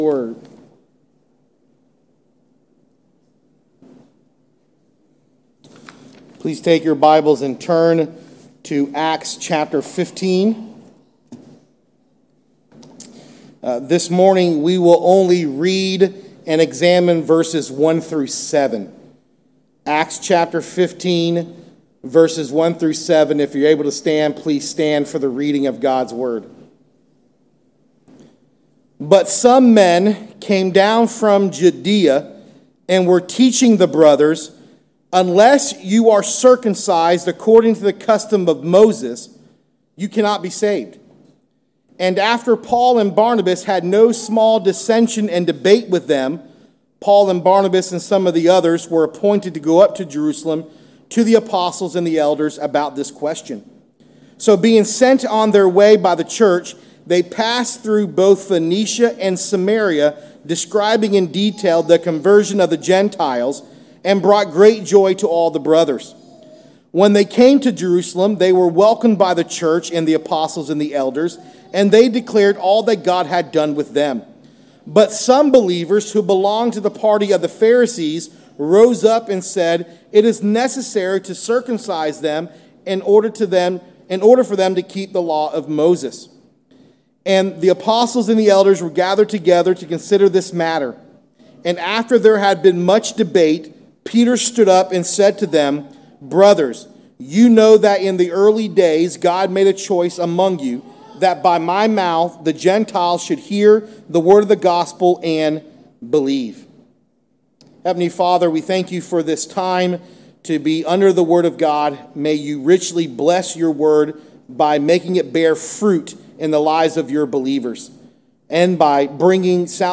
Sermons | Green Run Baptist Church
Sunday Morning Service